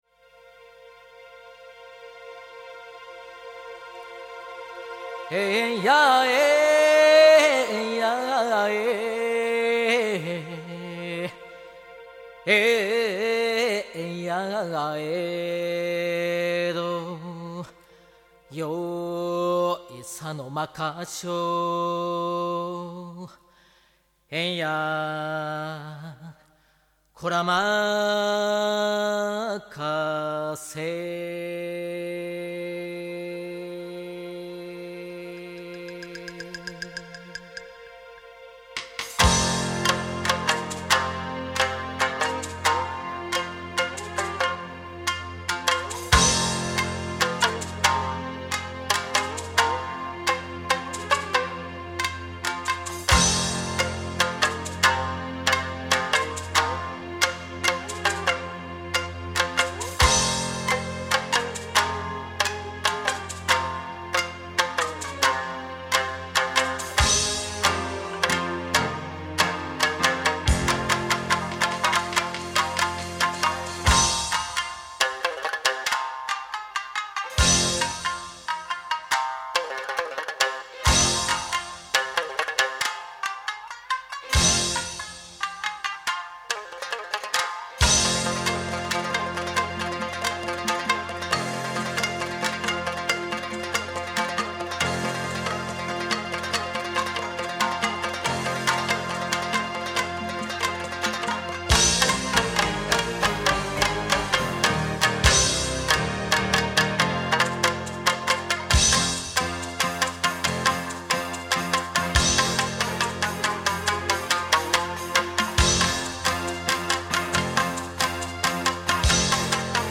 2015年の今も日々進化し続ける津軽三味線の今を体感して下さい！